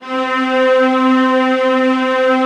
VIOLAS DN4-L.wav